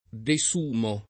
desumere [ de S2 mere ] v.;